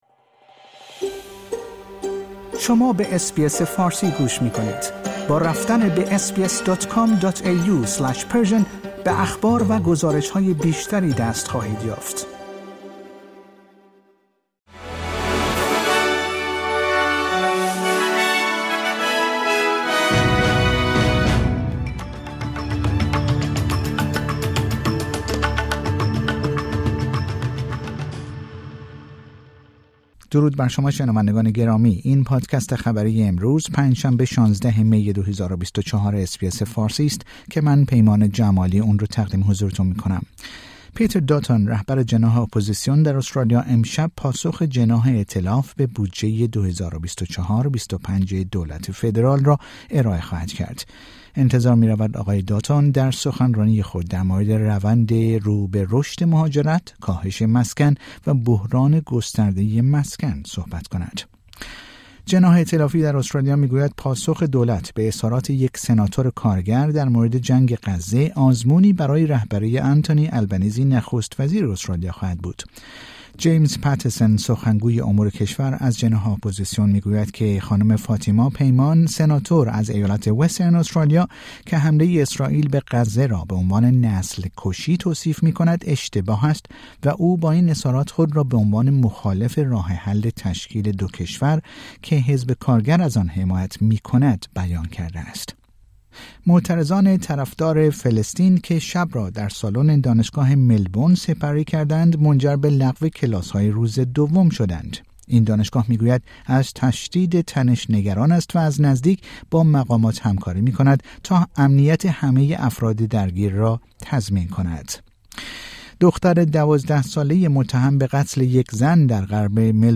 در این پادکست خبری مهمترین اخبار استرالیا، در روز پنج شنبه ۱۶ مه ۲۰۲۴ ارائه شده است.